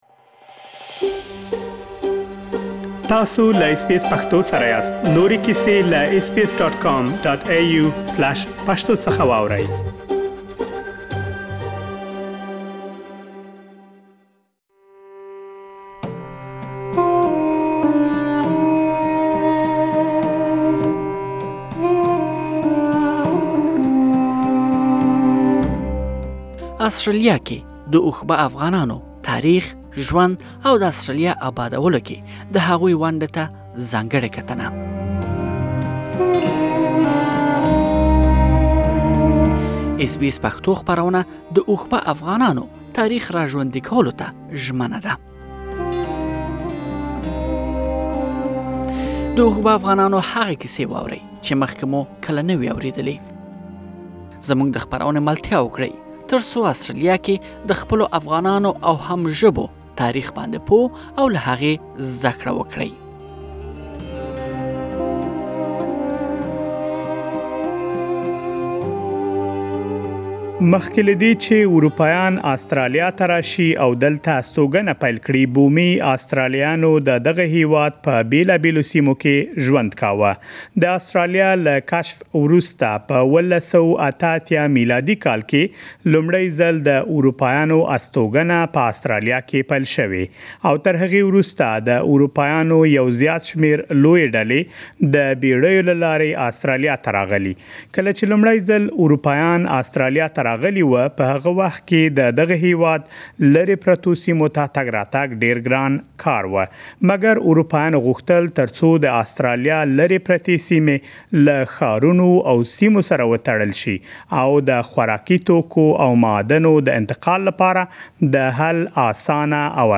معلوماتي راپور